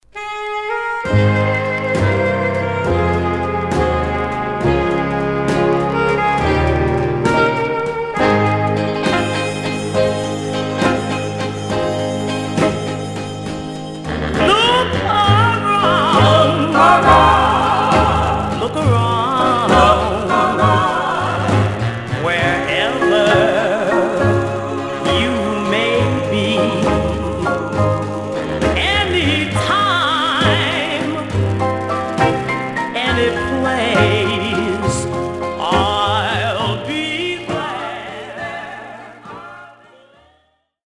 Stereo/mono Mono
Rythm and Blues Condition